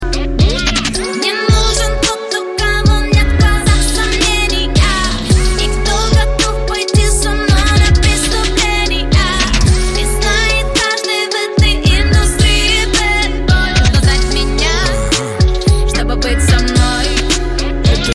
• Качество: 128, Stereo
Хип-хоп
русский рэп
качающие
красивый женский голос
Bass
классный бит